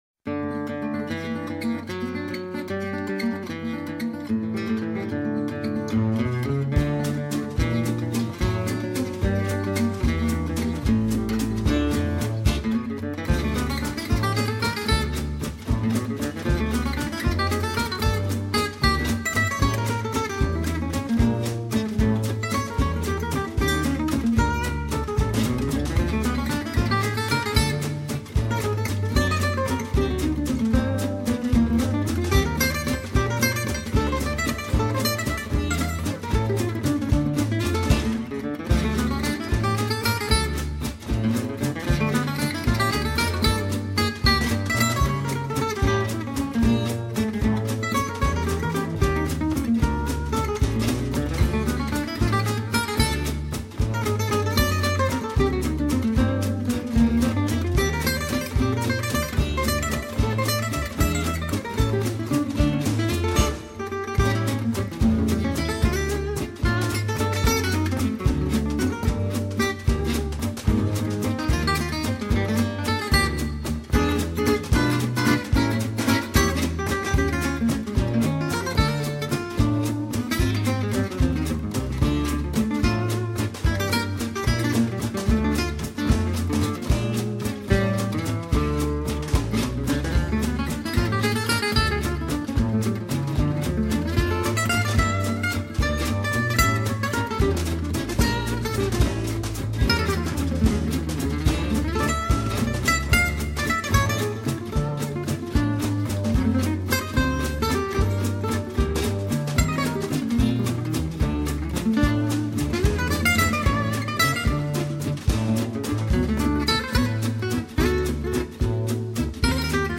seconde guitare
basse, batterie et percussions.
guitare manouche